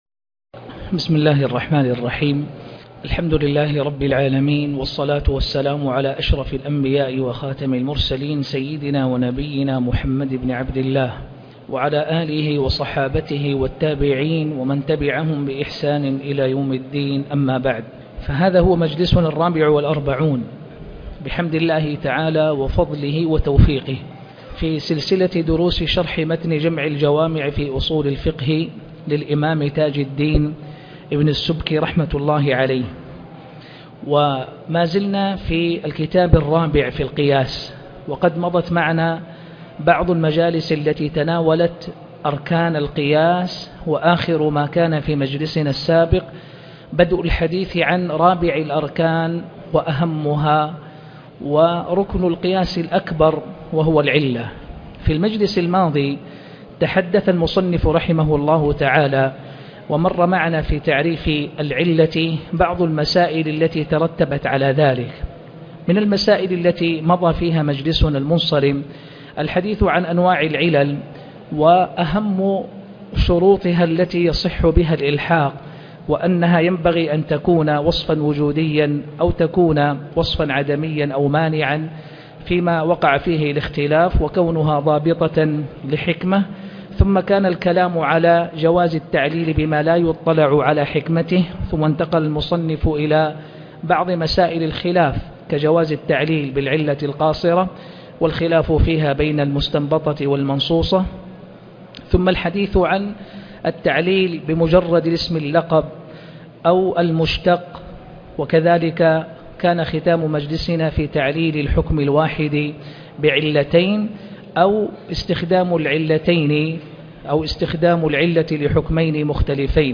شرح جمع الجوامع الدرس 44 - القياس _ الركن الرابع _ العلة 2